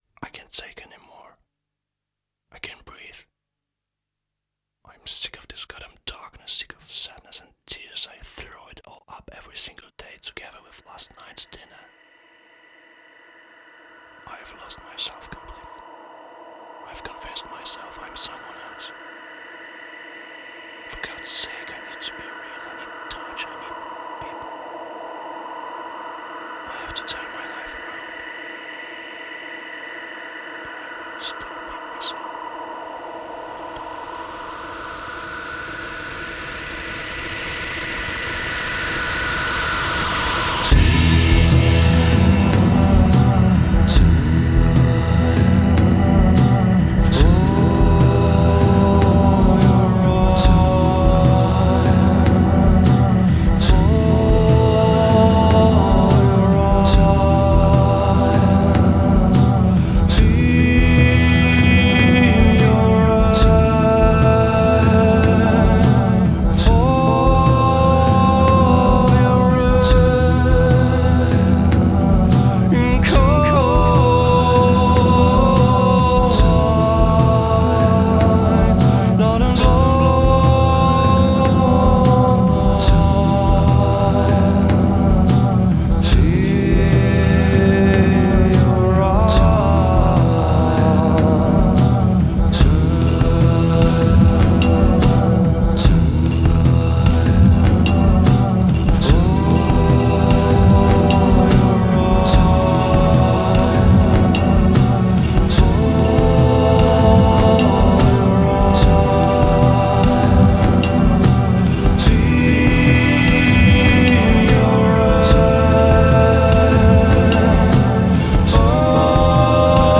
цікавий прогресив-рок гурт з Польщі.